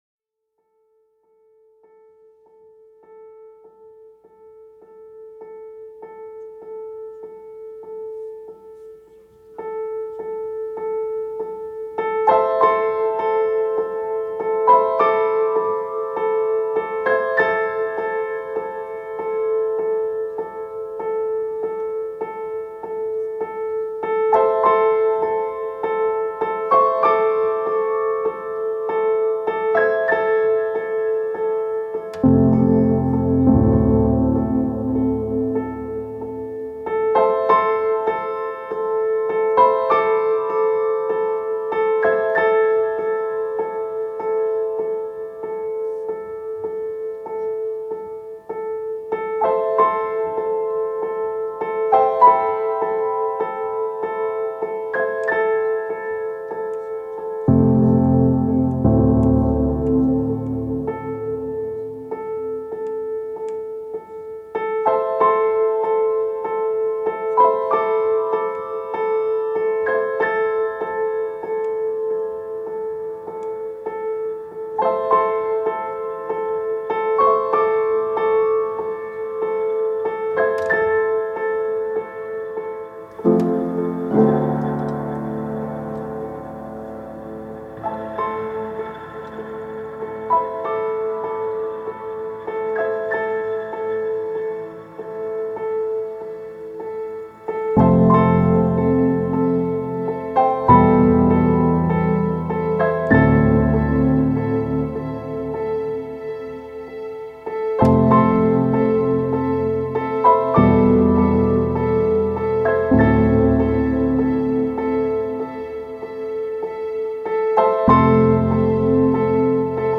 Delicate, repetitive piano awaiting for salvation.